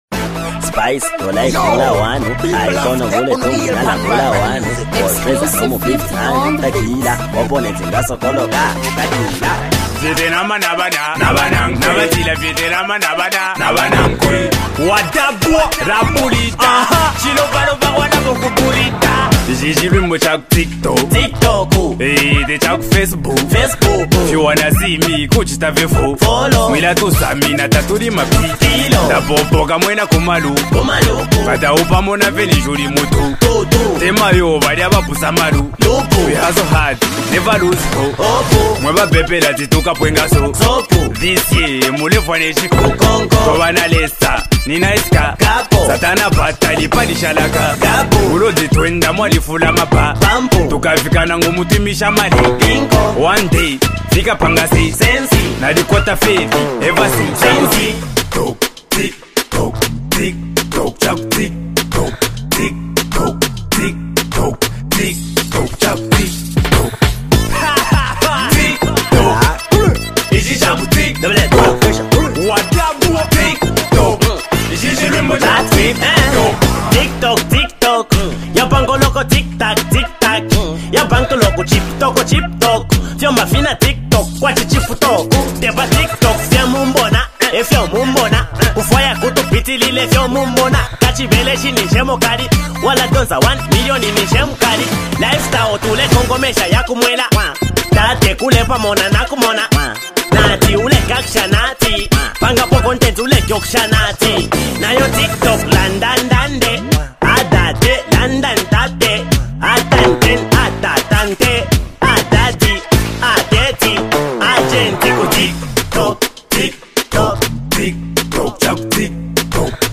hip-hop and rap
and the unstoppable energy of Zambian rap.